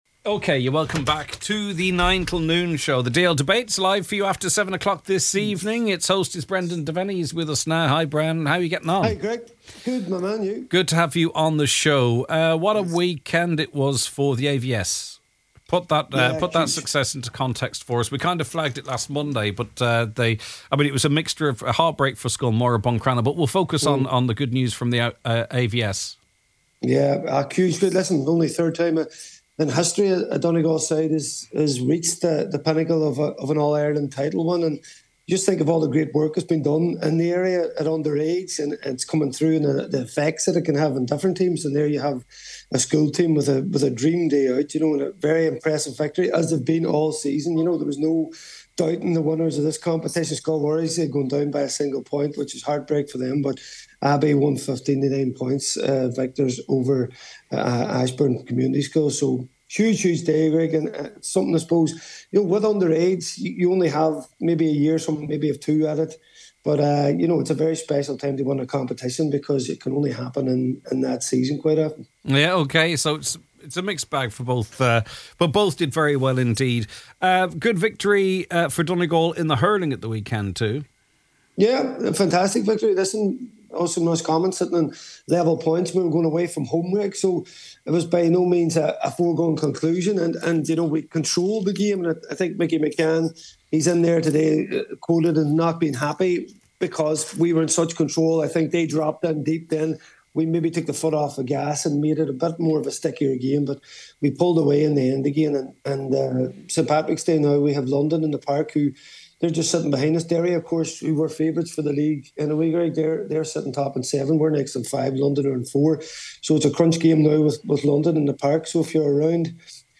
The Nine ’til Noon Show – Weekday’s 9am to 12noon Magazine type mid morning chat show which aims to inform and entertain listeners and to platform their views and issues: